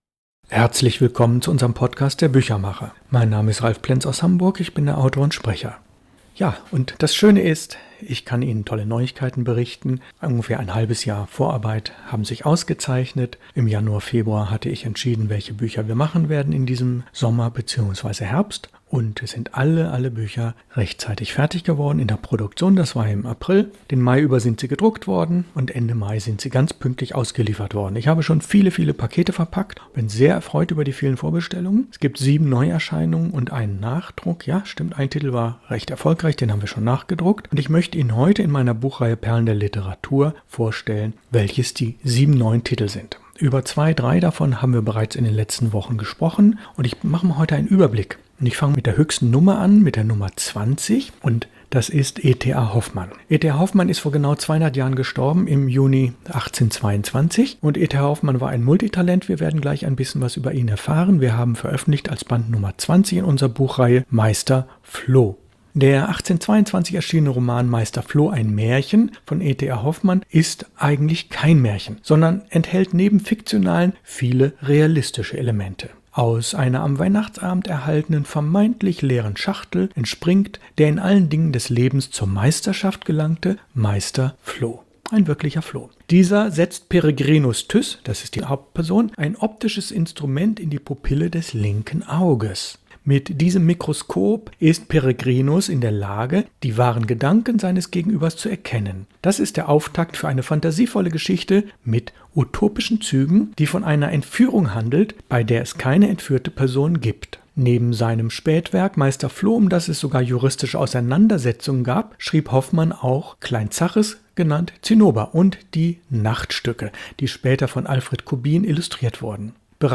Vierte Lesung der Biografie „Orlando“ von Virginia Woolf, Teil 4 von 4.